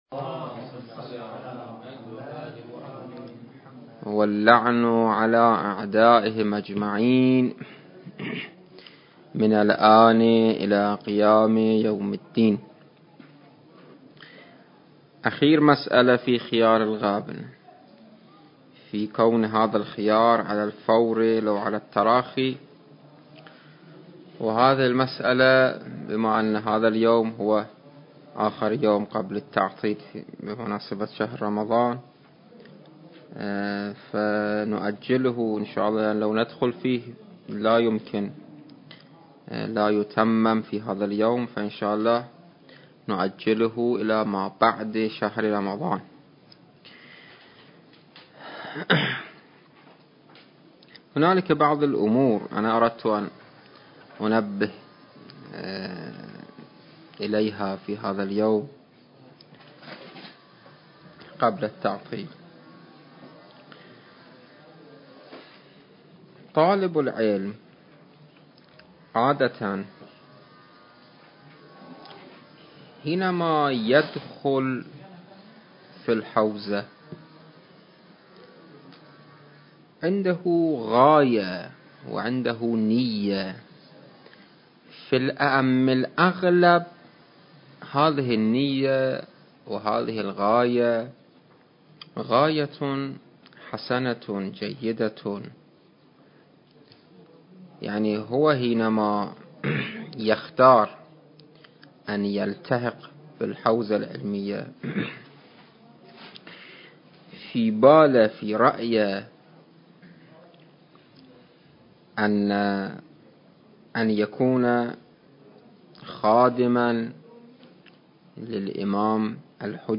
التاريخ: 2021 المكان: معهد المرتضى - النجف الأشرف